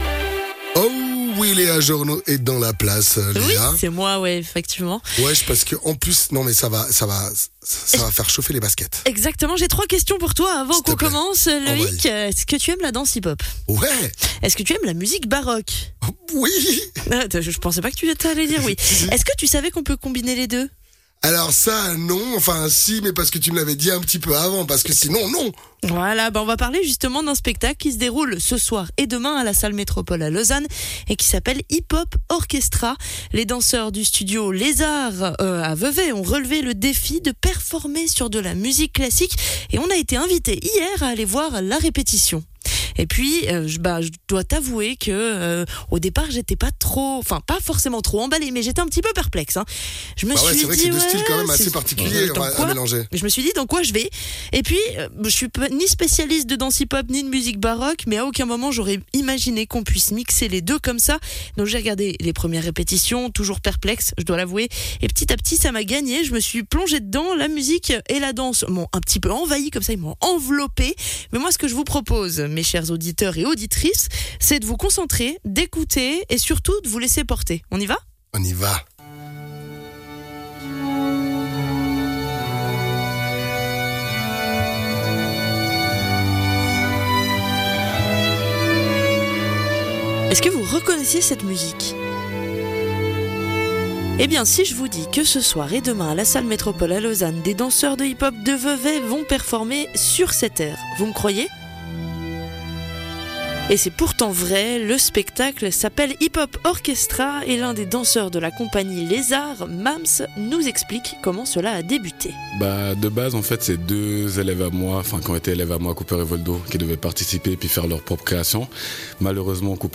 Intervenant(e) : Les danseurs du Studio LesZarts de Vevey